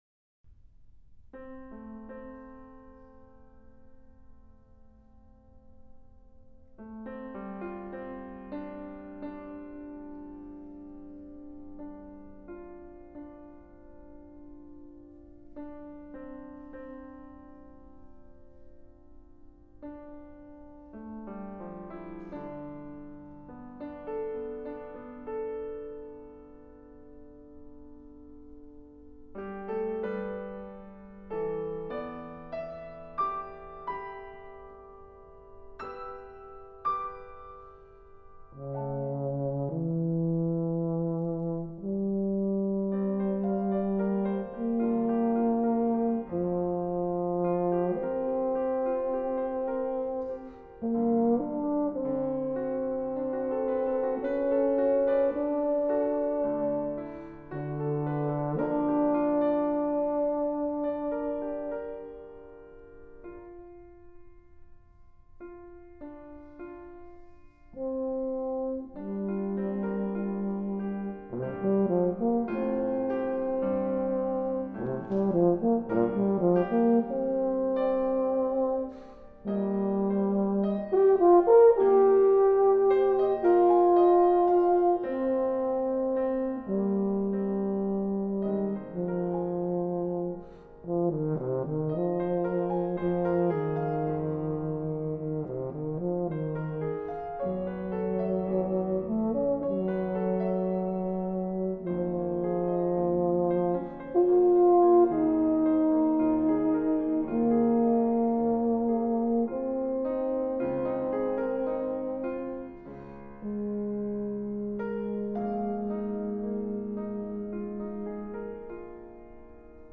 For Euphonium Solo
Arranged by . with Piano.